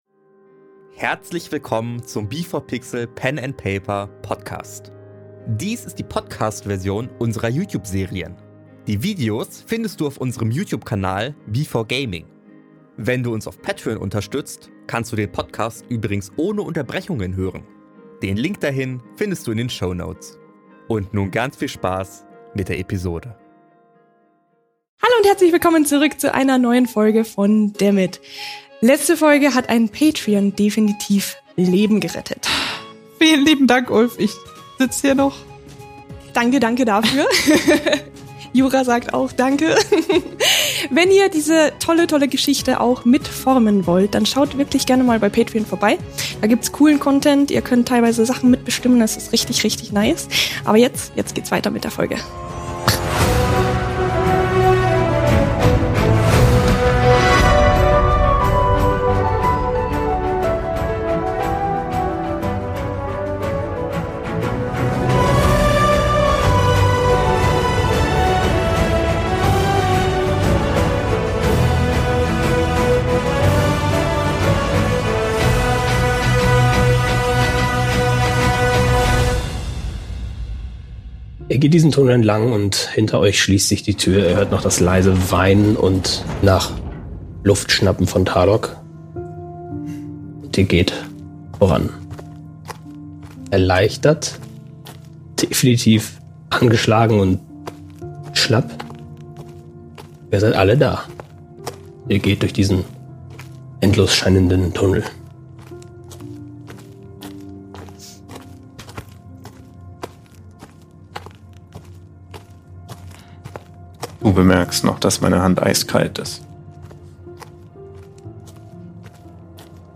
Wir spielen auf dem YouTube B4Gaming regelmäßig Pen and Paper und veröffentlichen dort unsere Serien. Dies hier ist die Podcast-Version mit Unterbrechungen.